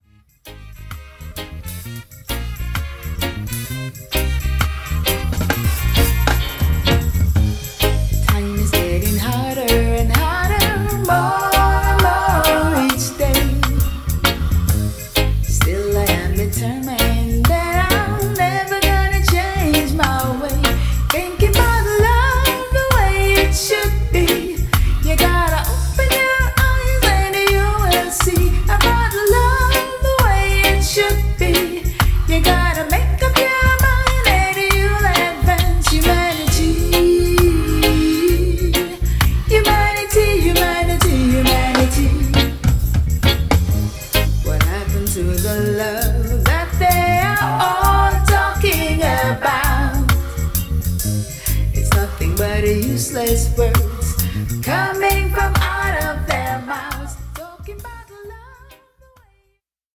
ホーム ｜ JAMAICAN MUSIC > UKレゲエ/ラバーズ